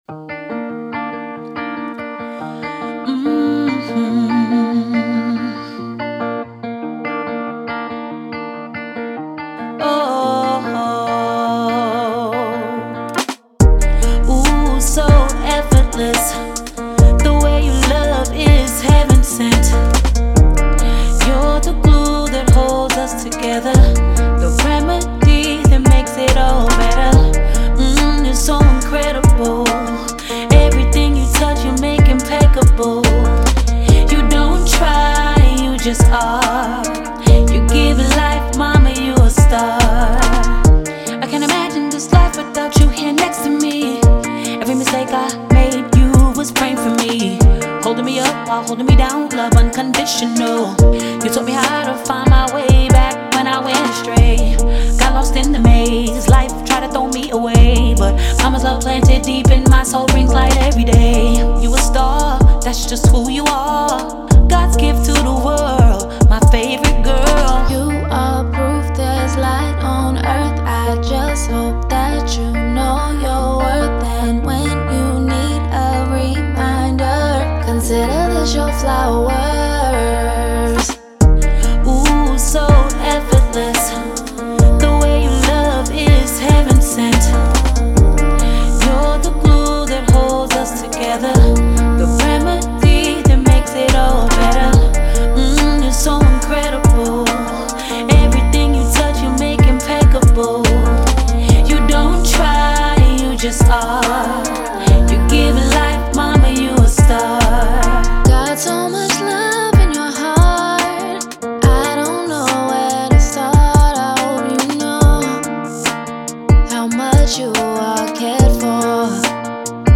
R&B, Pop
G Minor